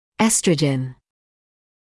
[‘estrəʤən][‘эстрэджэн]эстроген (British English oestrogen [‘iːstrəʤən])